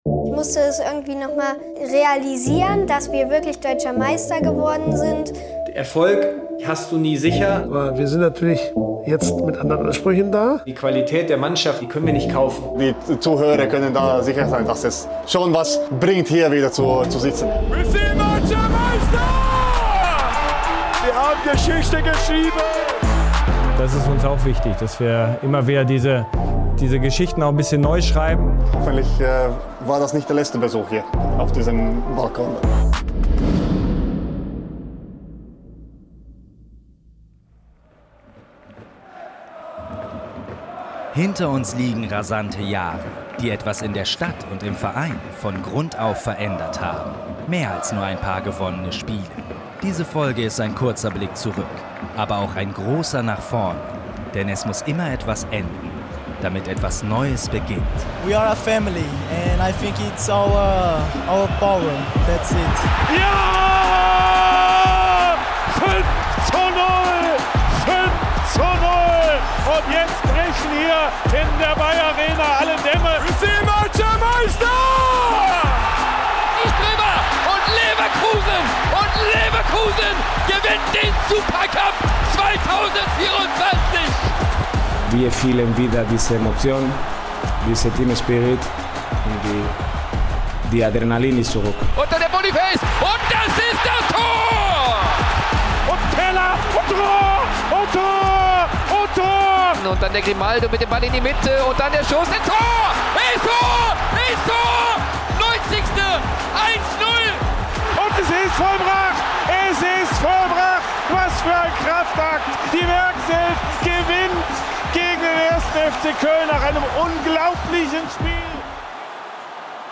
Documentary